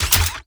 GUNMech_Reload_09_SFRMS_SCIWPNS.wav